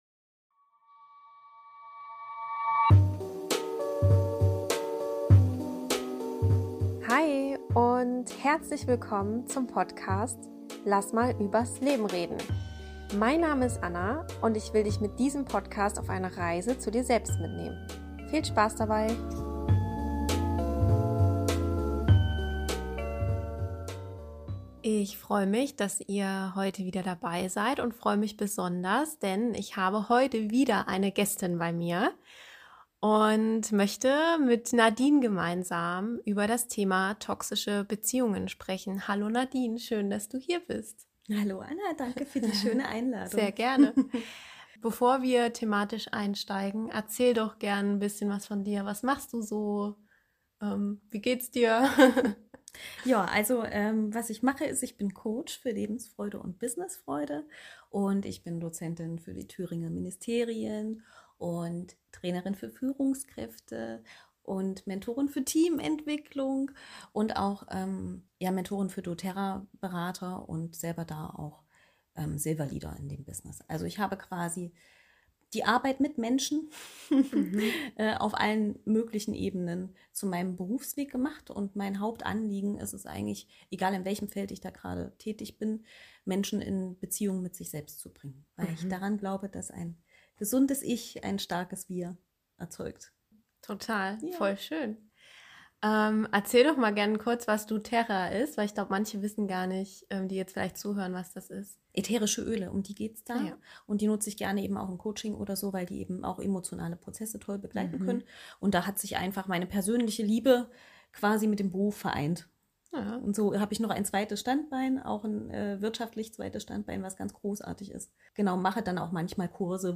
Für diese Podcastfolge habe ich mir eine Expertin eingeladen und freue mich sehr, dass sie als Gästin bei mir war.